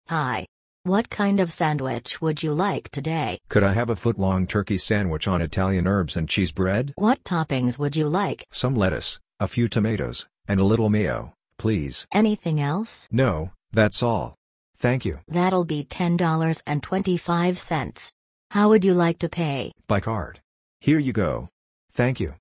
Conversation-at-Subway.mp3